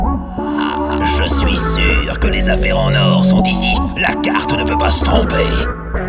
Mes créations audio J’ai téléchargé trois pistes audio et j’ai ensuite utilisé ces trois pistes stéréos pour pouvoir créer ma piste audio souhaiter en les assemblant.